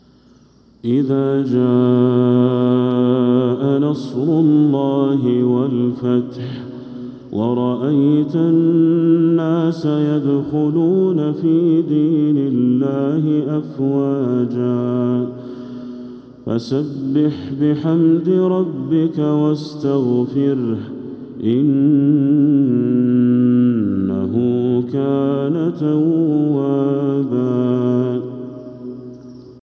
سورة النصر كاملة | شعبان 1446هـ > السور المكتملة للشيخ بدر التركي من الحرم المكي 🕋 > السور المكتملة 🕋 > المزيد - تلاوات الحرمين